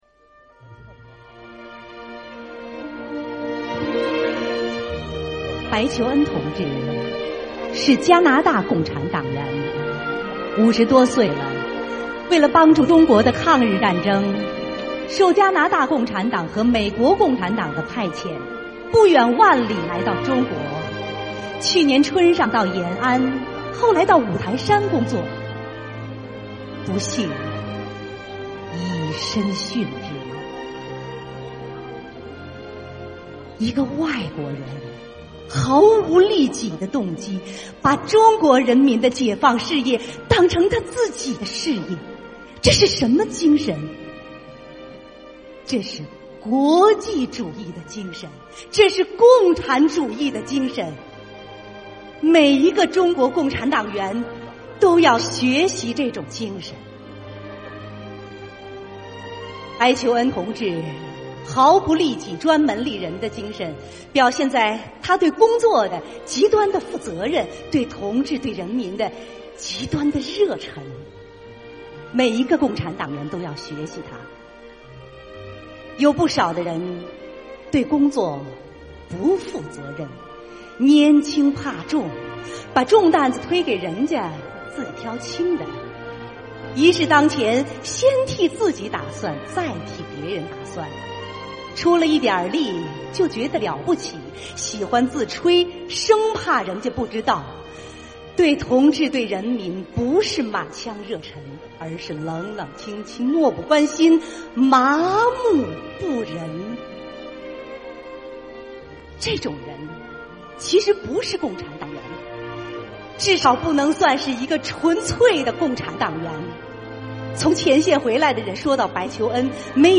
[9/9/2018]【配乐朗诵】：著名播音员海霞朗诵毛泽东著作《纪念白求恩》（节选）